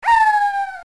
(Links throughout this page point to mp3s from the game.)
high-pitched war cry in defeat.